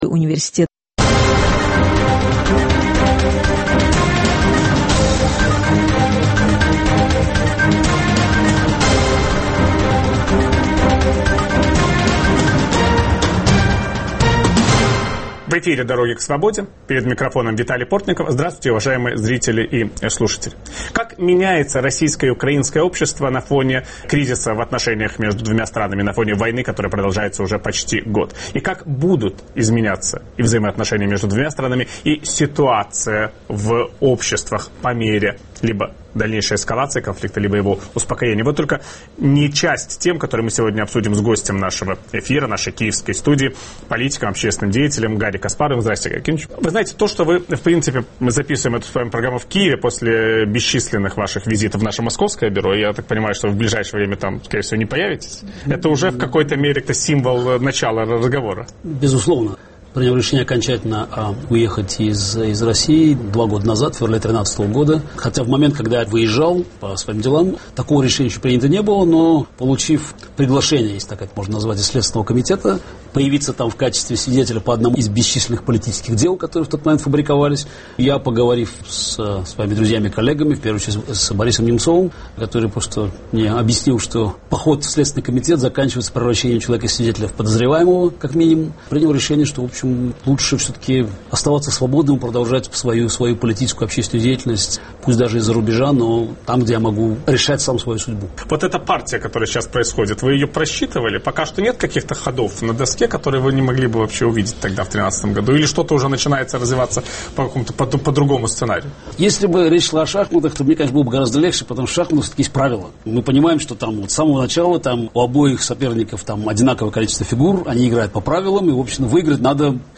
Гость программы Виталия Портникова "Дороги к свободе" - политик, общественный деятель, 13-й чемпион мира по шахматам Гарри Каспаров.